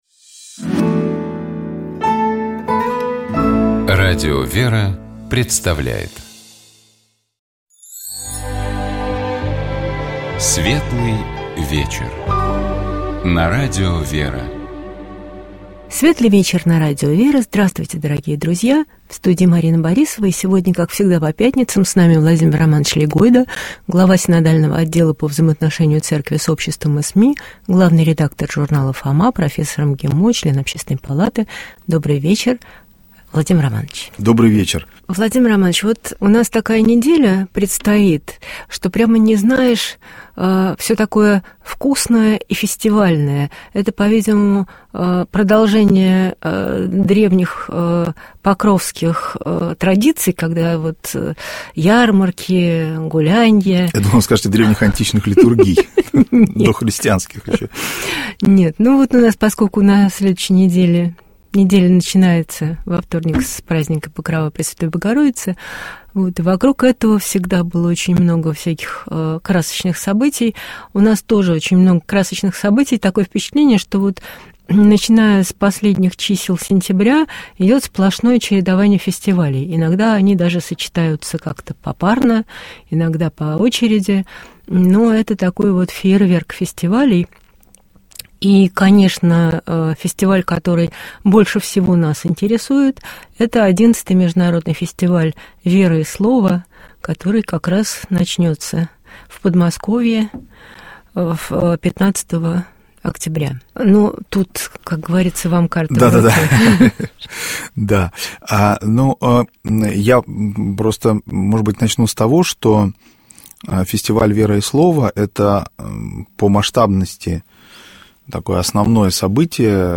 О православном Символе веры — епископ Тольяттинский и Жигулёвский Нестор.